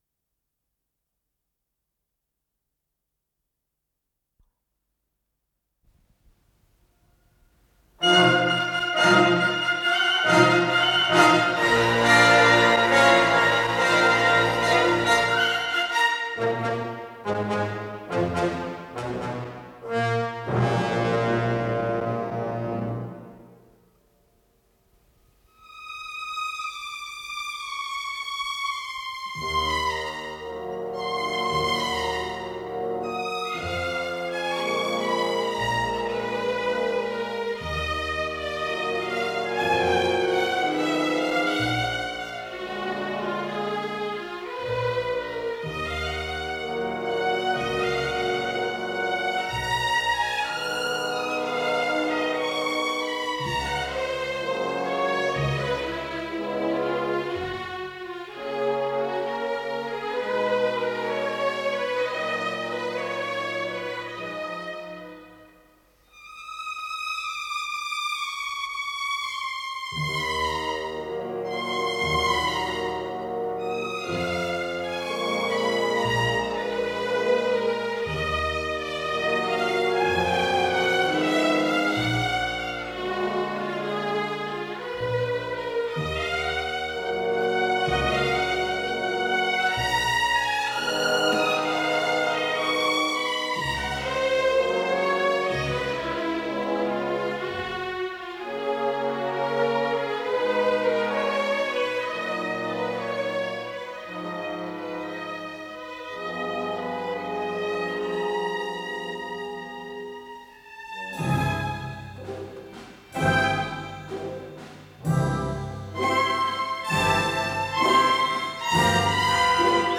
Скорость ленты38 см/с
ВариантМоно